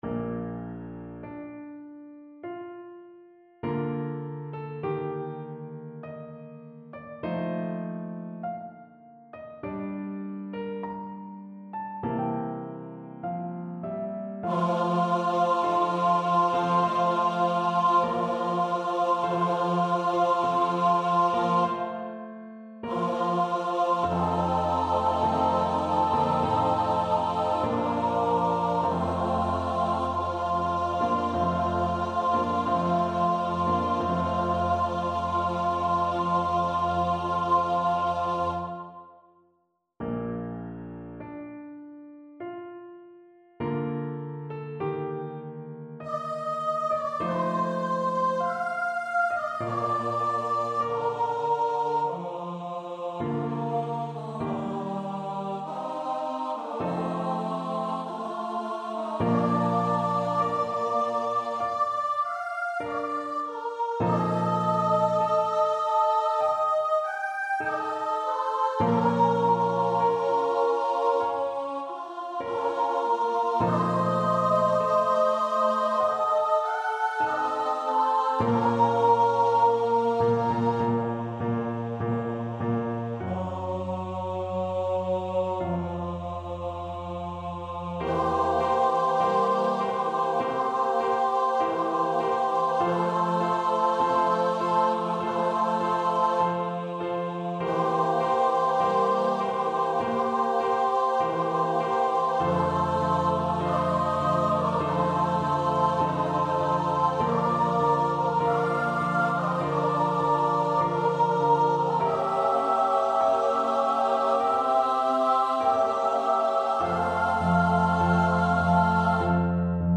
Free Sheet music for Choir
Classical (View more Classical Choir Music)